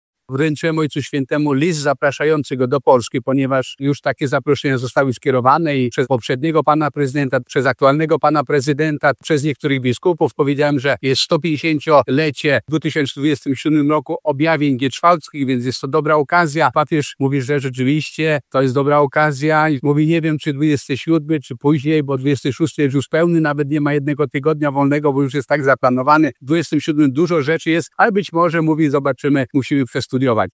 01Abp-Wojda-po-audiencji.mp3